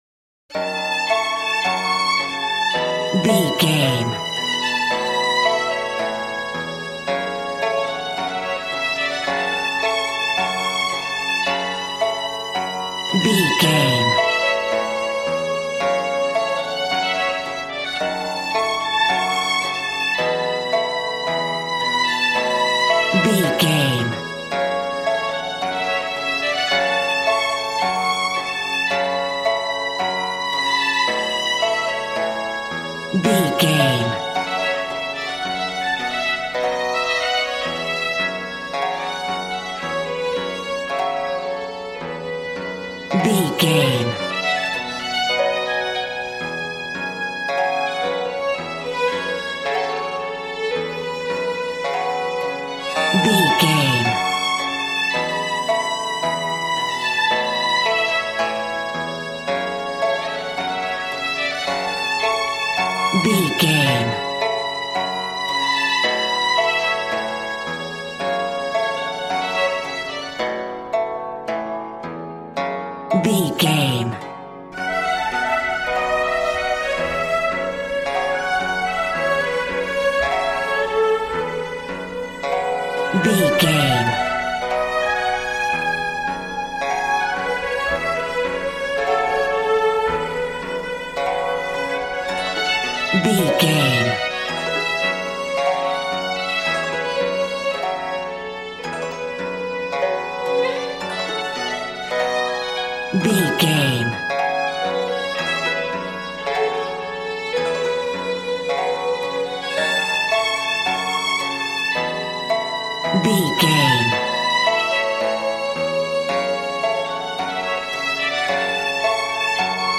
Ionian/Major
happy
bouncy
conga